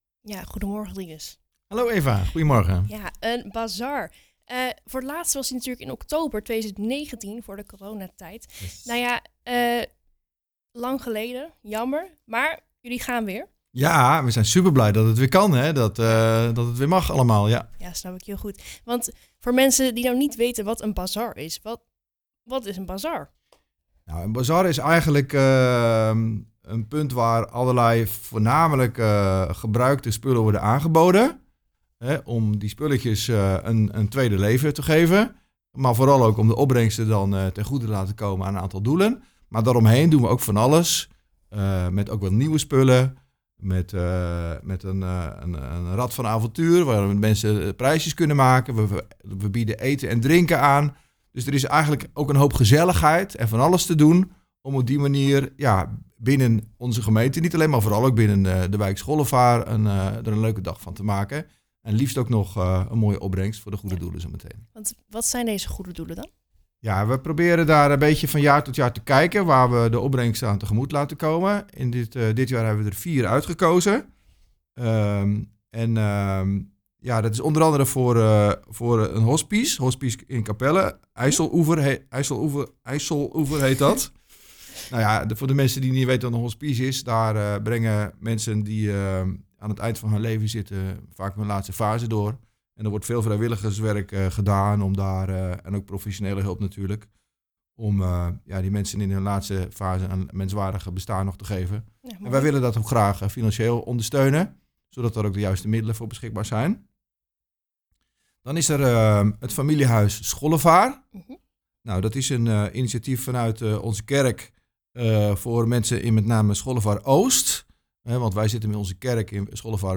In de studio